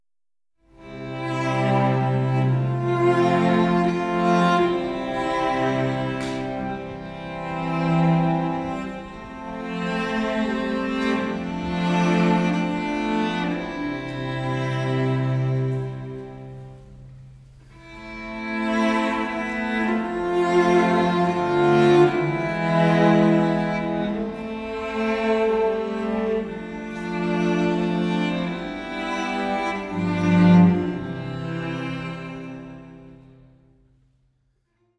Violine, Orgel